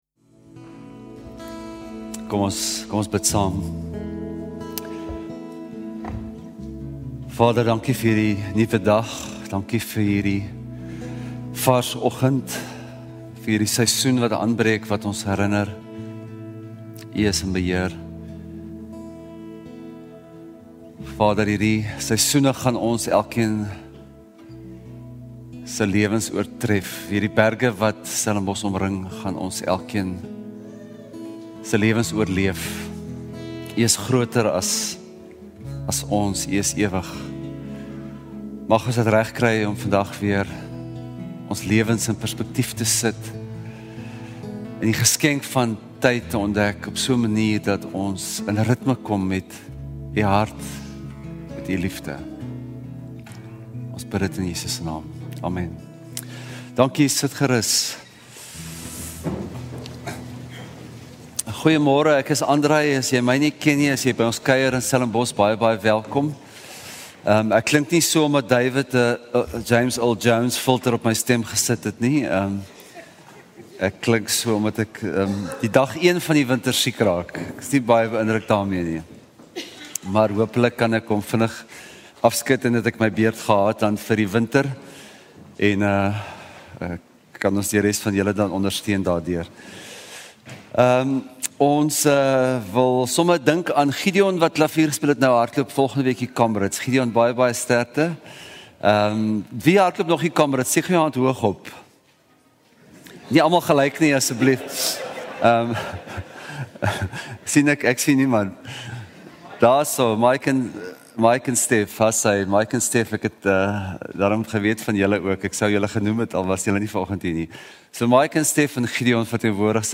Stellenbosch Gemeente Preke 02 Junie 2024 || Wanneer Is Ons?